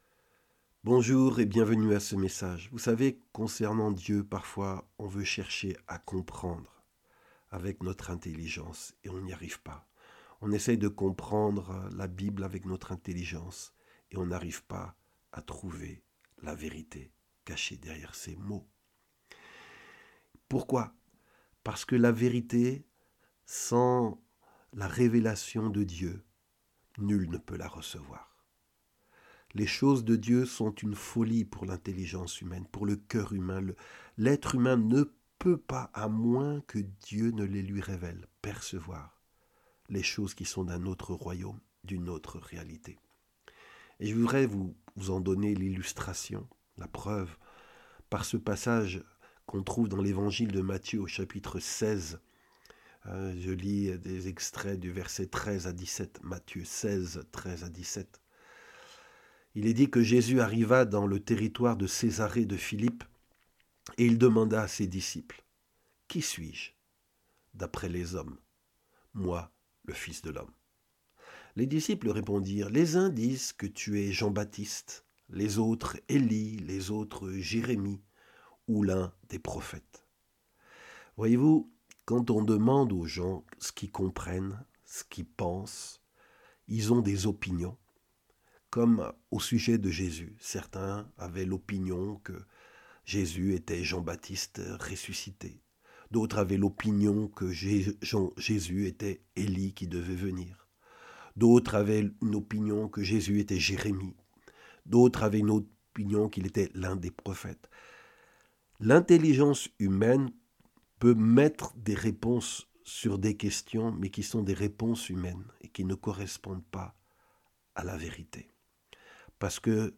Des messages audio chrétiens